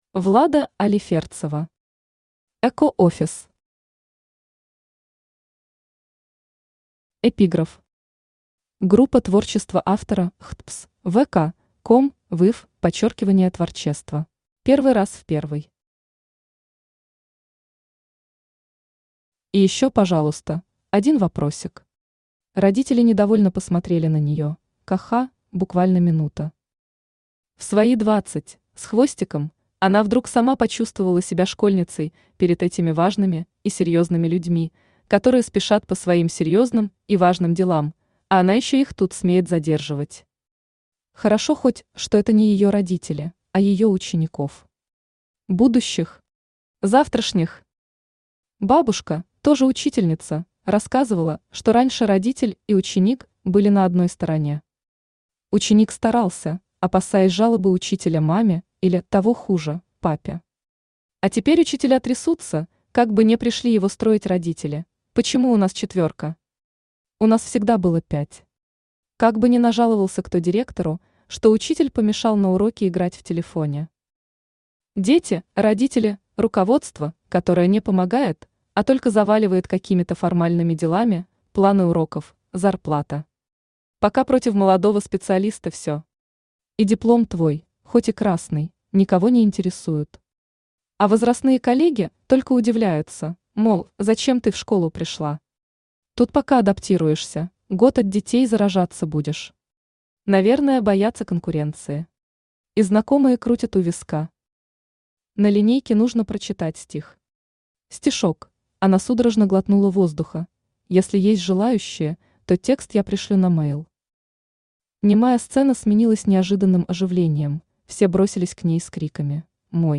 Aудиокнига Эко-офис Автор Влада Алиферцева Читает аудиокнигу Авточтец ЛитРес.